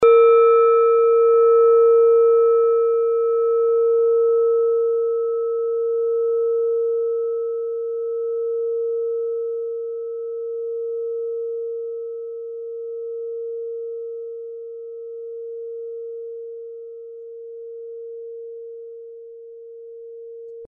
Klangschale Nepal Nr.17
Klangschale-Durchmesser: 14,2cm
(Ermittelt mit dem Filzklöppel)
Oktaviert man diese Frequenz 37mal, hört man sie bei 229,43 Hz, das ist auf unserer Tonleiter nahe beim "B".
klangschale-nepal-17.mp3